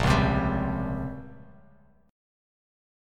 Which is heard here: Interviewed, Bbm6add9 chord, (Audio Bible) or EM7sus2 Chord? Bbm6add9 chord